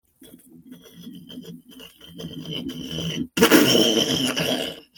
volcano-eruption.mp3